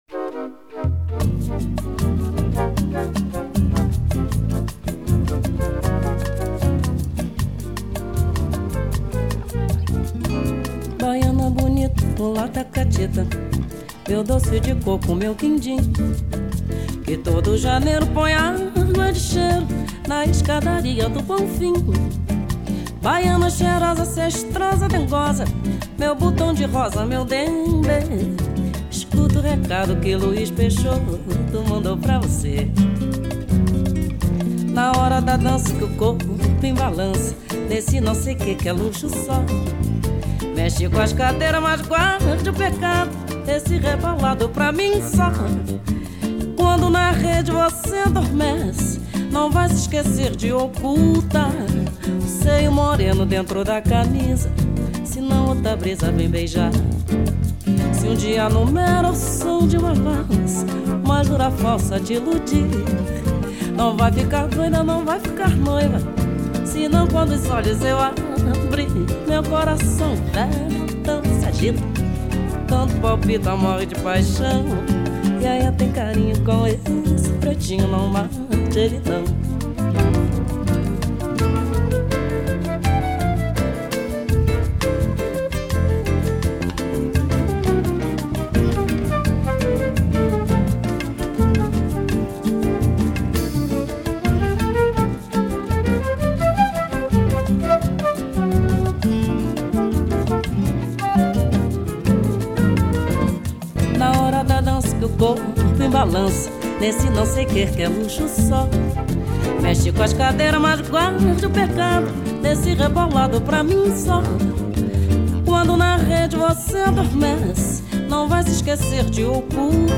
gesang
latin